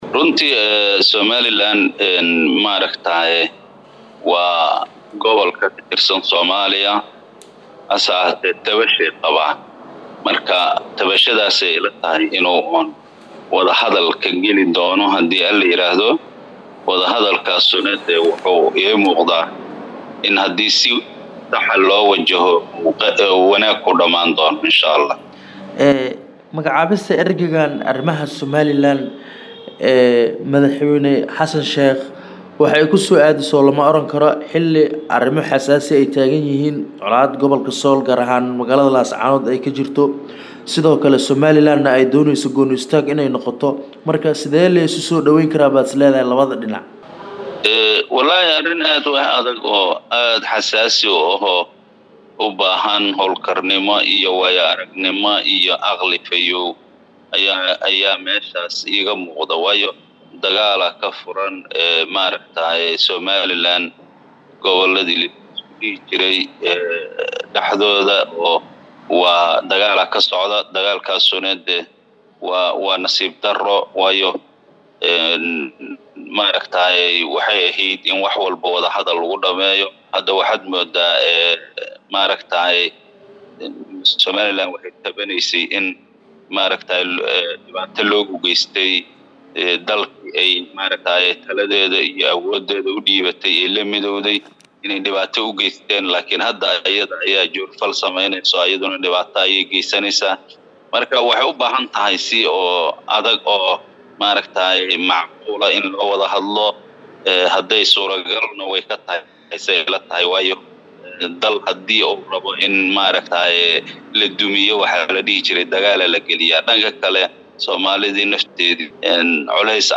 Halkaan Hoose ka dhageyso waraysiga Xildhibaan hore Xuseen Maxamuud Maxamed Gaagaale: